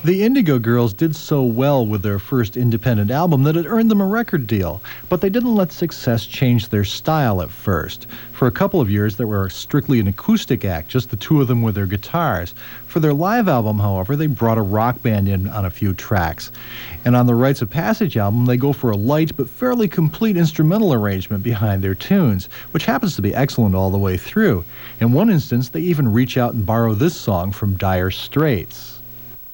lifeblood: bootlegs: 1992-xx-xx: wbfo - buffalo, new york
05. reviewer (0:29)